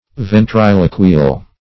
Ventriloquial \Ven`tri*lo"qui*al\